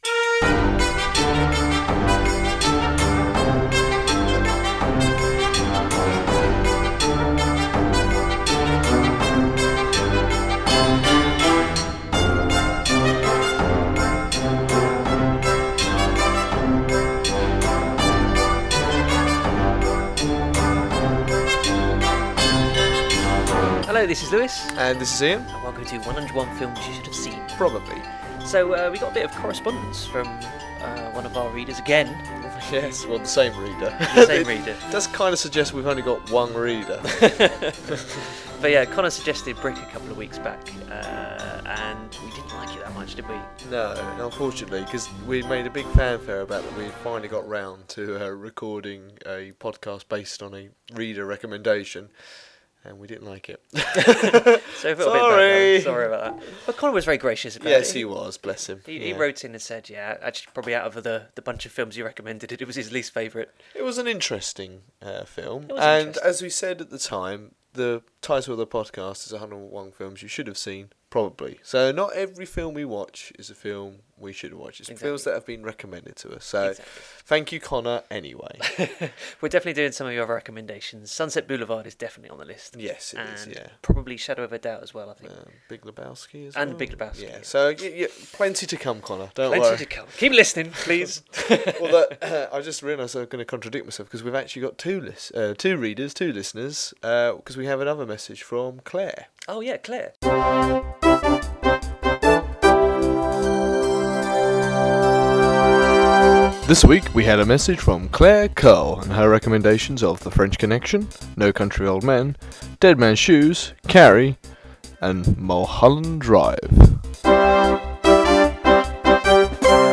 This week we tackle David Lynch’s Mulholland Drive, another reader recommendation (we’ve given up on calling you all ‘listeners’, there’s just such an alliterative appeal about ‘reader recommendation’). Importantly, this gives us a chance to wheel out our ‘news’ jingle, which we always look forward to, so please do write in so we can use it every week.